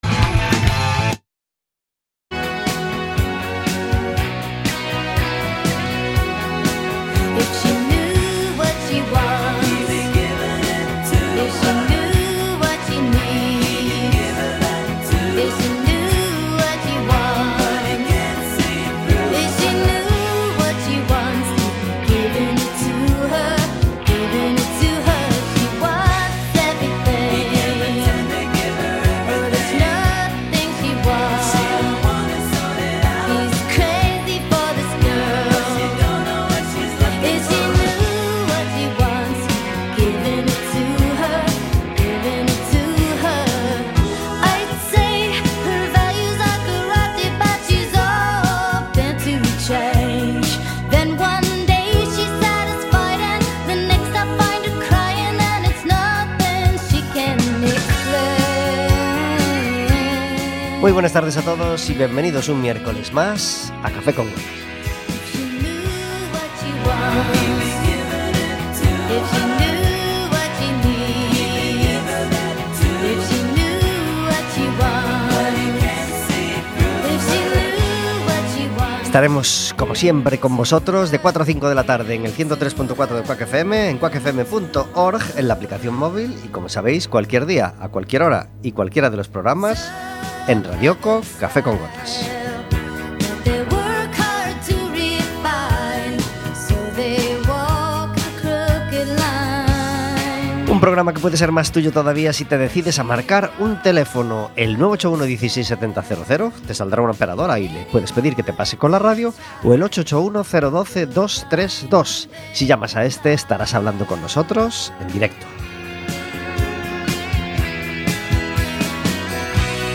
Un invitado cada día